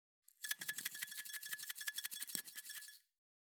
356七味を振る,一味,唐辛子,調味料,カシャカシャ,サラサラ,パラパラ,ジャラジャラ,サッサッ,ザッザッ,シャッシャッ,シュッ,パッ,サッ,トントン,カラカラ,
効果音厨房/台所/レストラン/kitchen